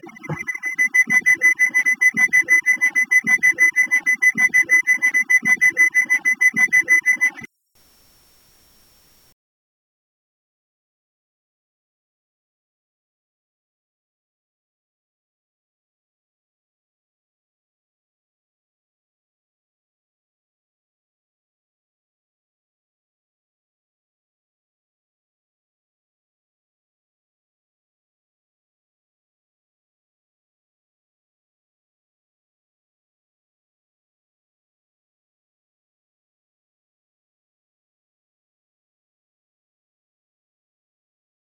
Alarm
Category ⚡ Sound Effects